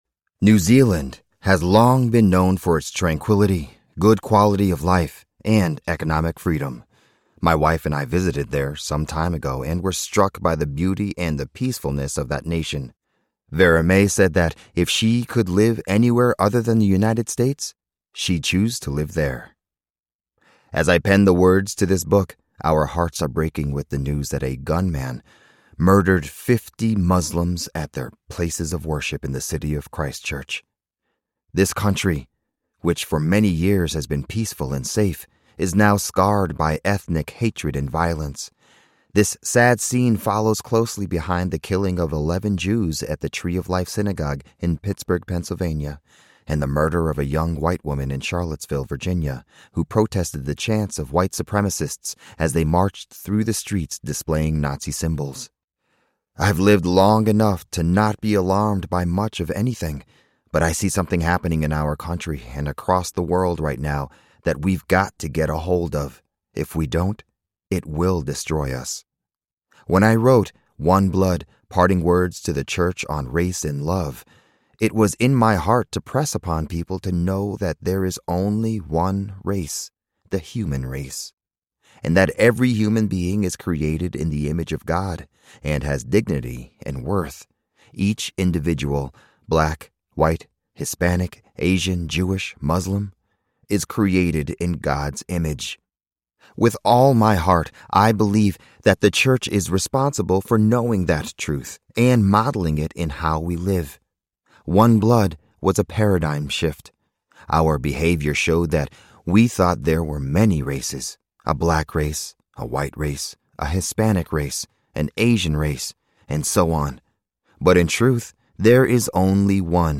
He Calls Me Friend Audiobook
4.4 Hrs. – Unabridged